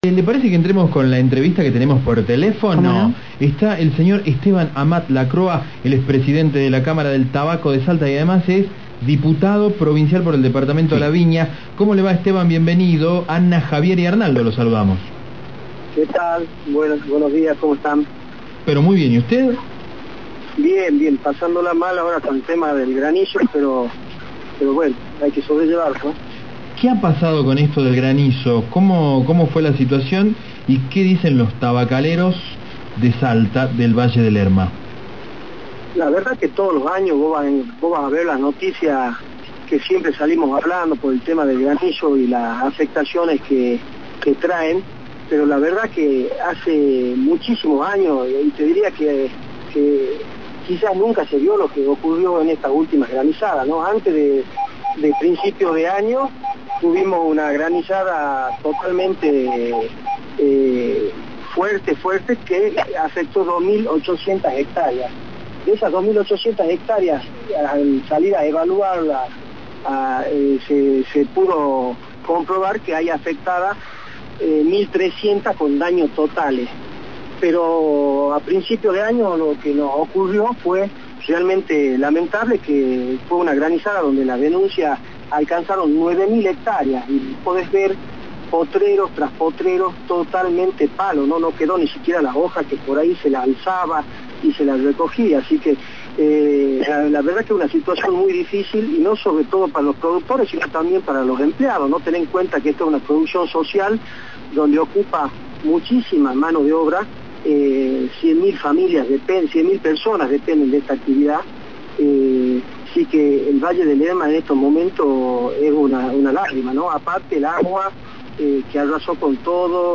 Reportaje de AM840